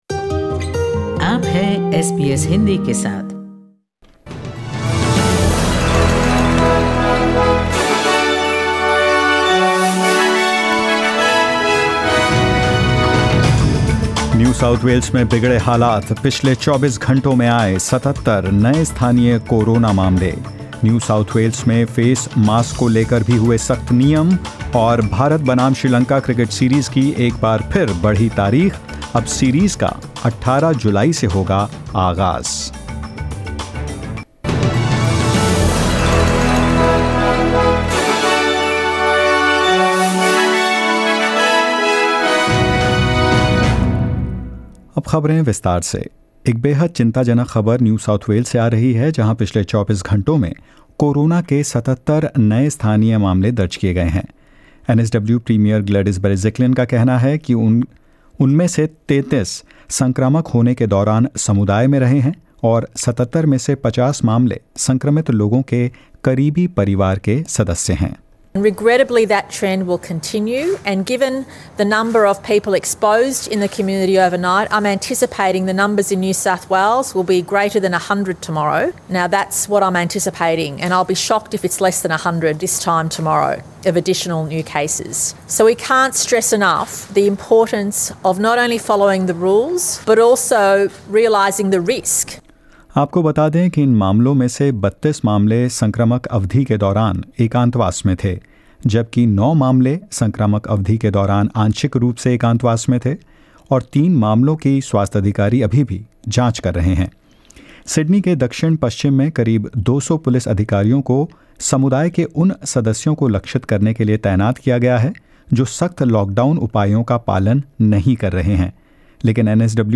In this latest SBS Hindi News bulletin of Australia and India: Victoria will close its borders to New South Wales from midnight tonight; Sri Lanka-India series to begin on July 18, new fixtures announced, and more.